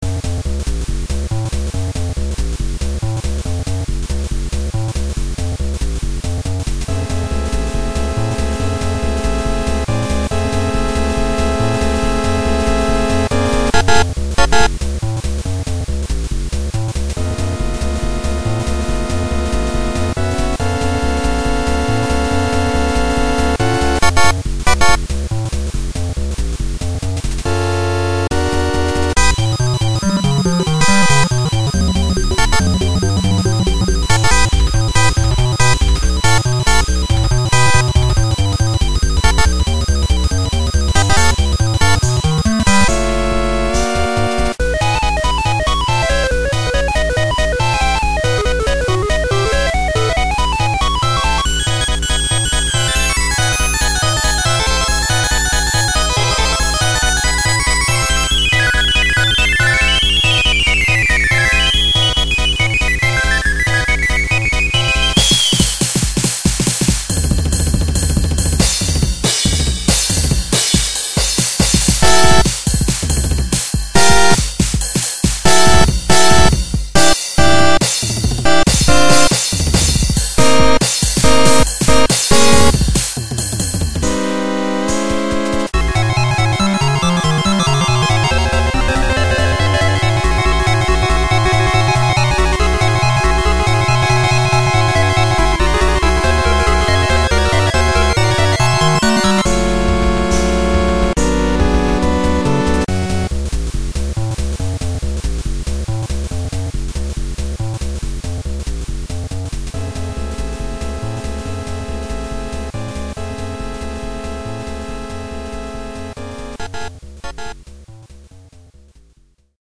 jeez I took the time to do this? this is pxtone it sounds like, damn